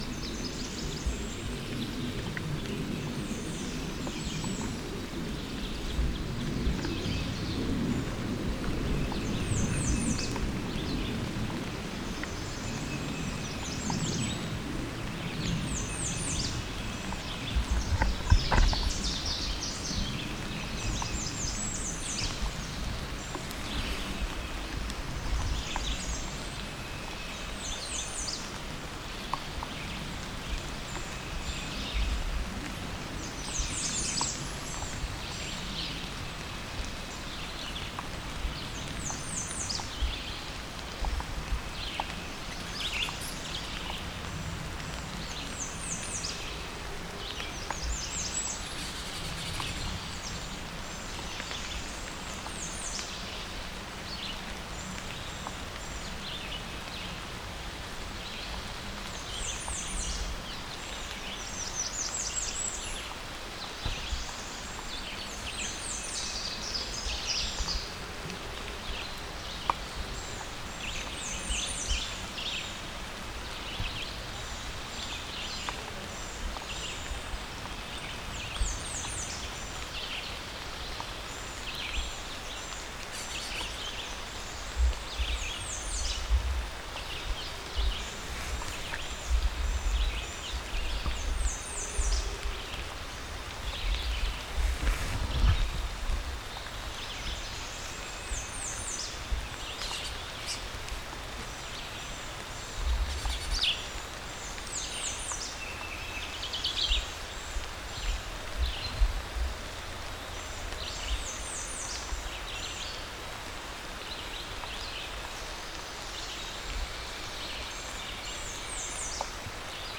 Red River Gorge has wonderful mixed forests, cliffs, riparian areas and amazing scenery, so bring your camera. Below is a clip of the sounds of this ancient forest.